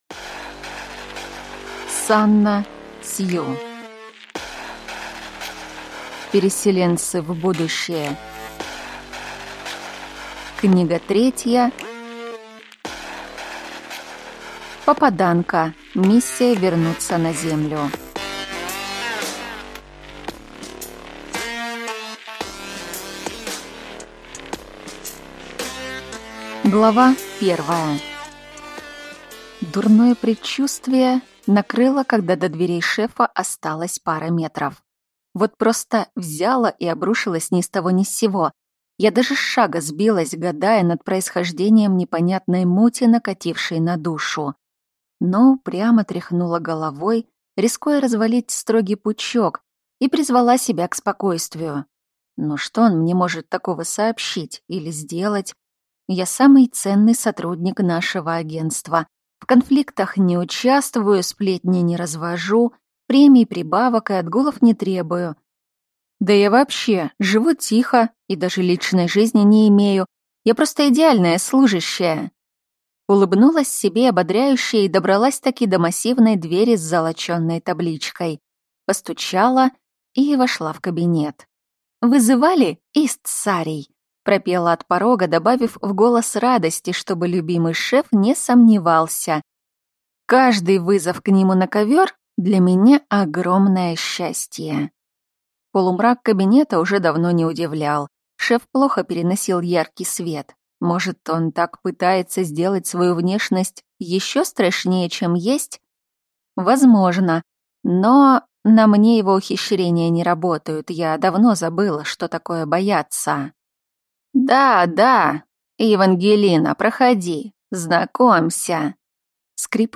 Аудиокнига Переселенцы в будущее. Книга 3. Попаданка: миссия вернуться на Землю | Библиотека аудиокниг